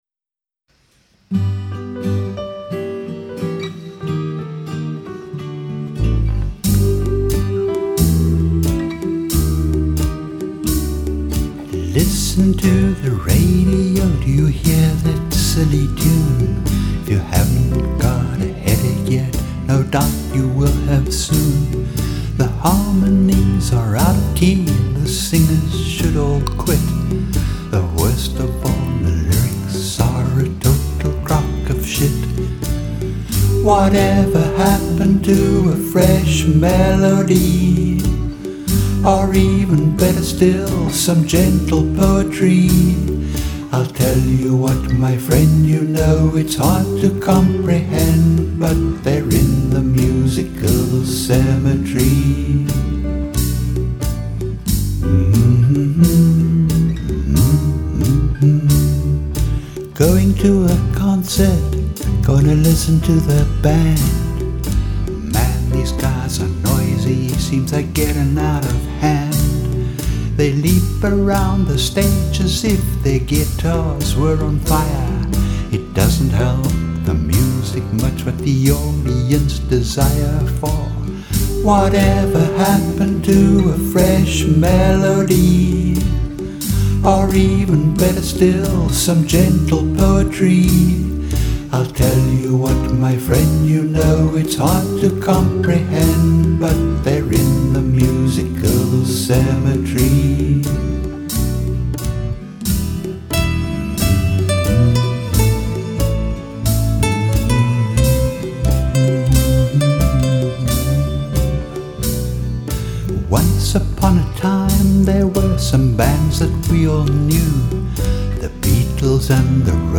The drums come out of a Boss drum box (not programmed!).
I wrote all the songs and play and sing everything.